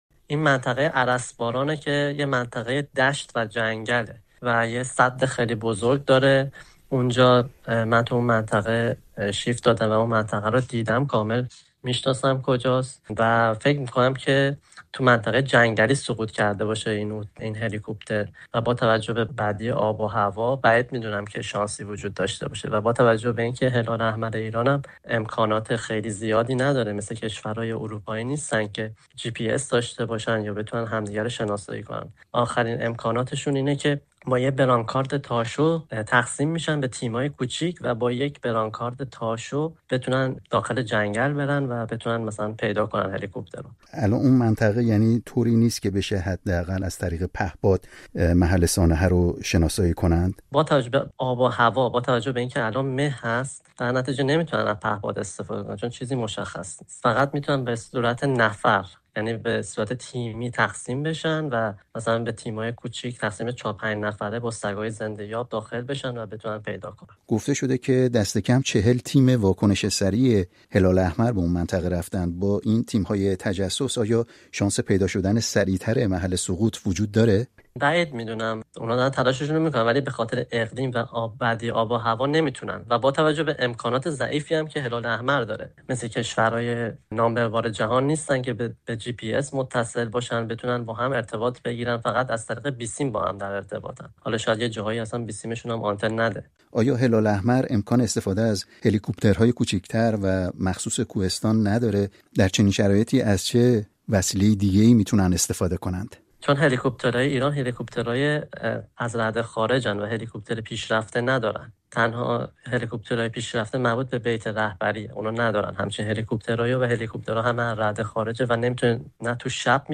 گفت‌وگوی کوتاهی